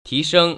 [tíshēng] 티성